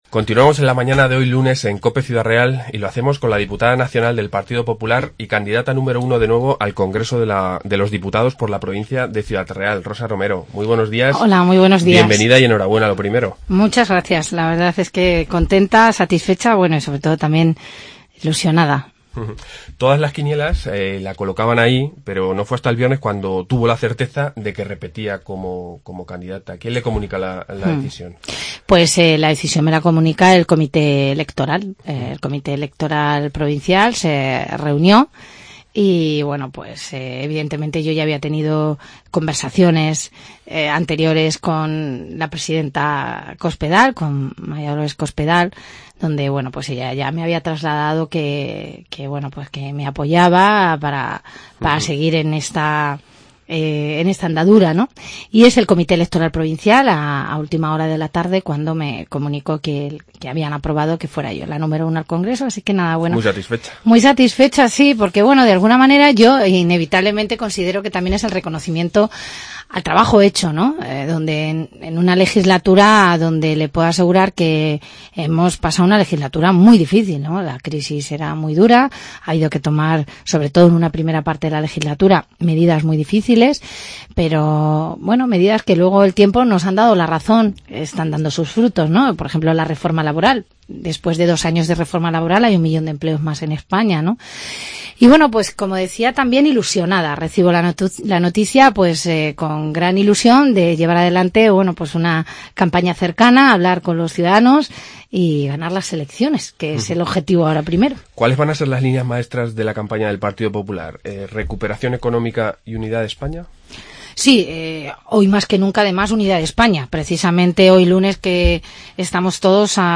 Entrevista Rosa Romero, diputada nacional del PP 9-11-15
AUDIO: Rosa Romero repite como candidata número 1 al Congreso por Ciudad Real. Hoy ha estado con nosotros en "La Mañana" de Cope Ciudad Real.